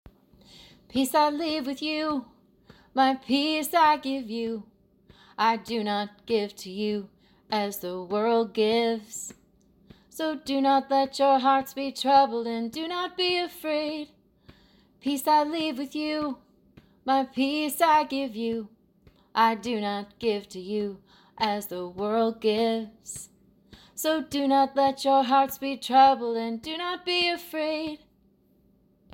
Peace-acapella-0.mp3